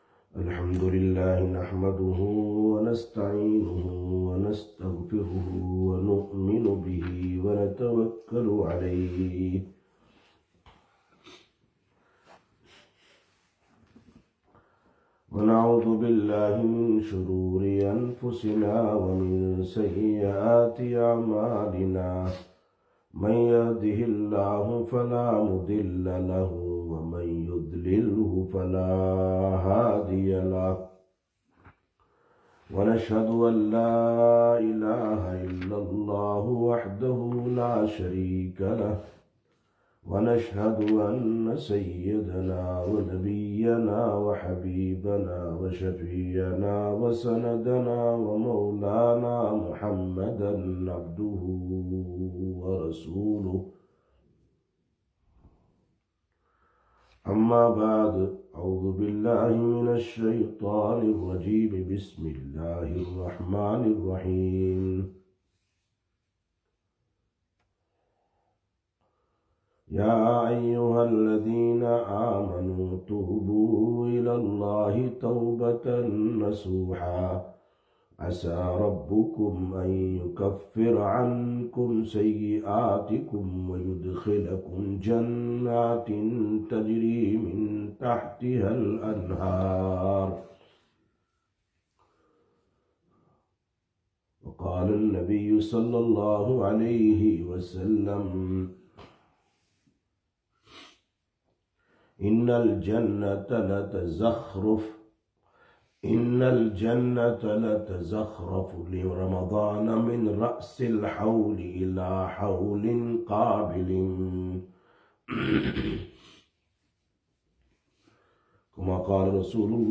04/03/2026 Sisters Bayan, Masjid Quba